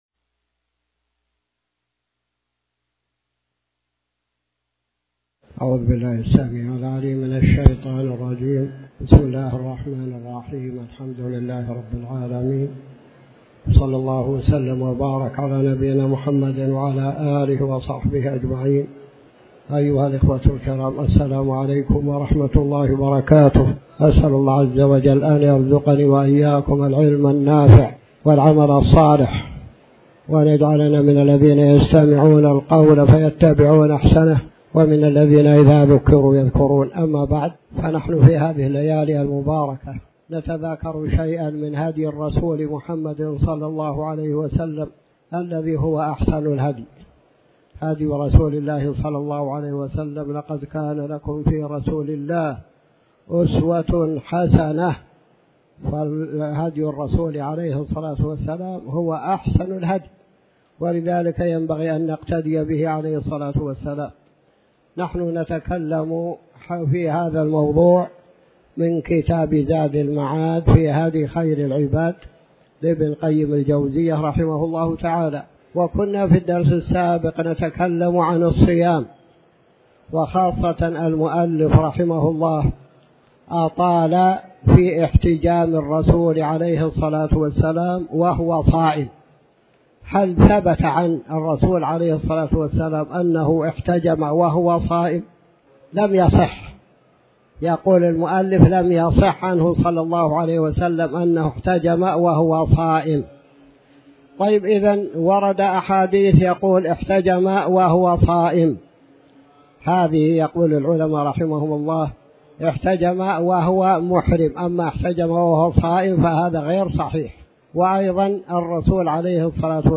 تاريخ النشر ١٩ محرم ١٤٤٠ هـ المكان: المسجد الحرام الشيخ